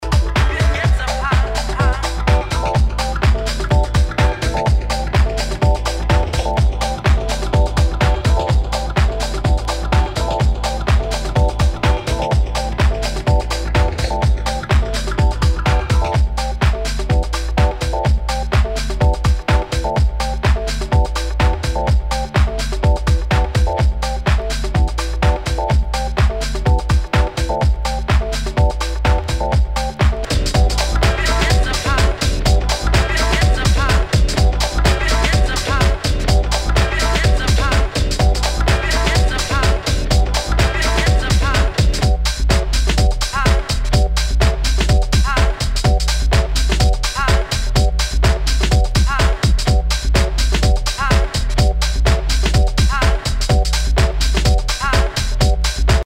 HOUSE/TECHNO/ELECTRO
ナイス！ファンキー・ハウス！